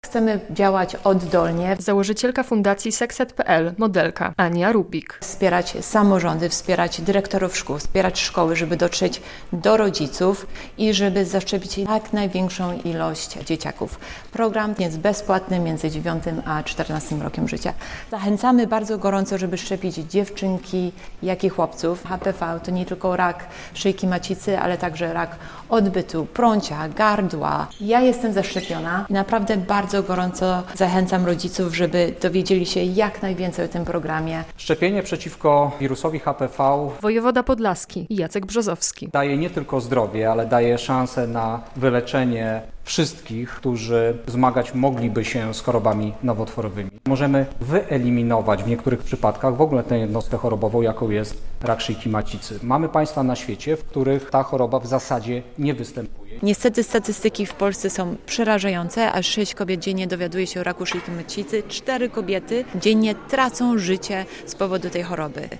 Anja Rubik i akcja szczepień przeciwko HPV - relacja